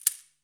15_shaker.wav